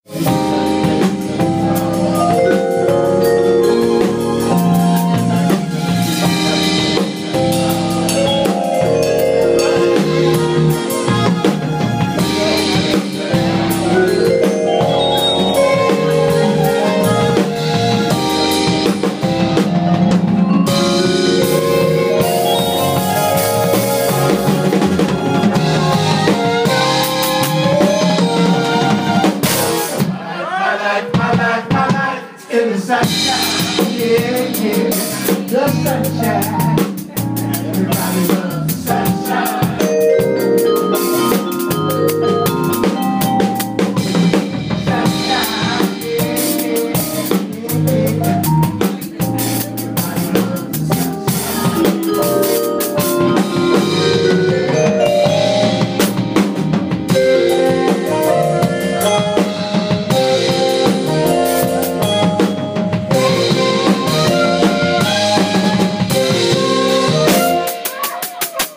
Live at Blues Alley, Washington DC, 21st Jan 2017